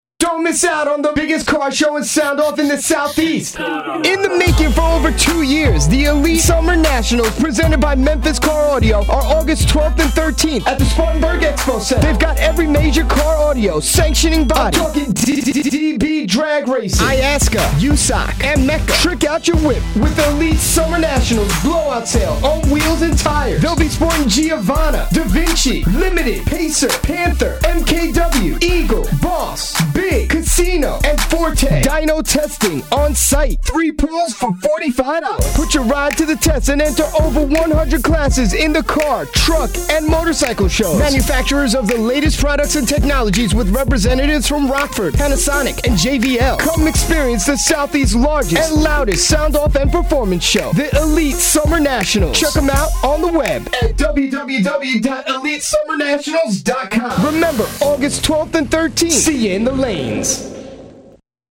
.....................LOCAL RADIO ADS